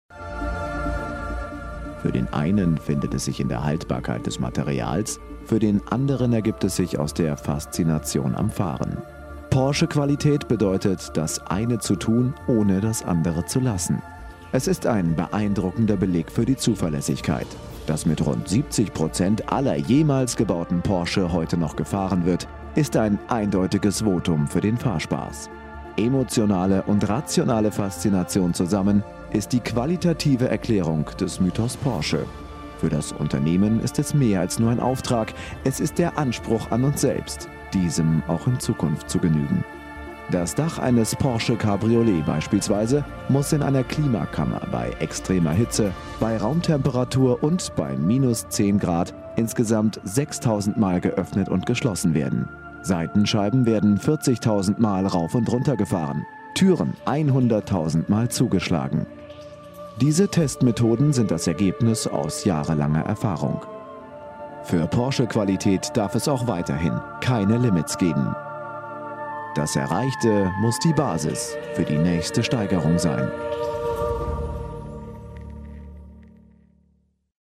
deutscher Profi-Sprecher. TV, Radio, Werbung, Synchron
Kein Dialekt
Sprechprobe: Werbung (Muttersprache):
german voice over artist